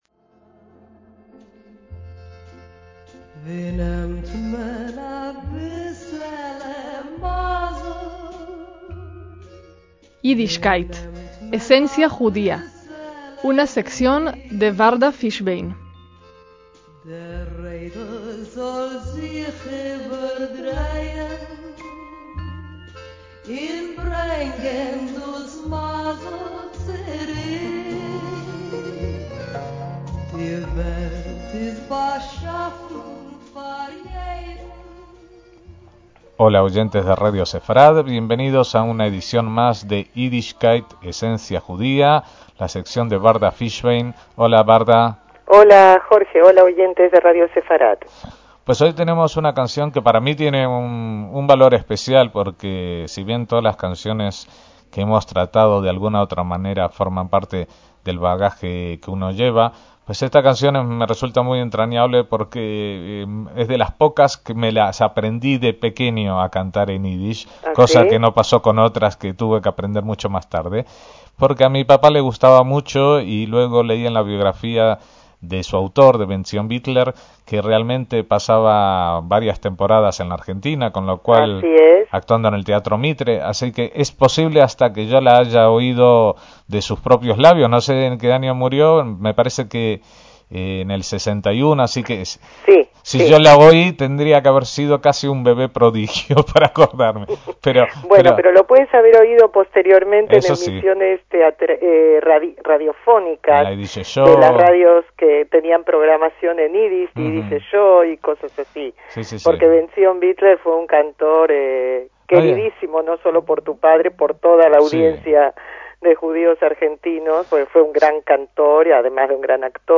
Ya en la década de 1930 era un artista afamado en la Europa del Este, la gente acudía multitudinariamente a ver los espectáculos en los que intervenía, y merece destacarse su voz cálida y profunda, ¡inconfundible!